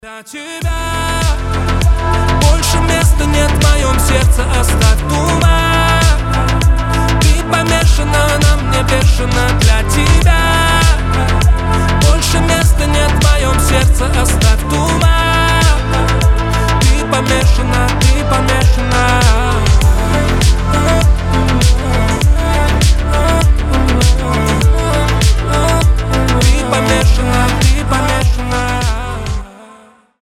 • Качество: 320, Stereo
мужской голос